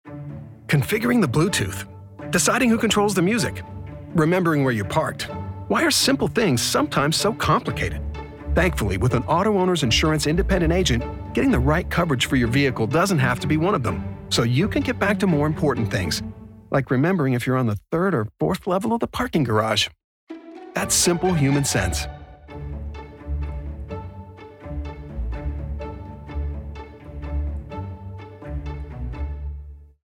Auto Insurance Radio Ads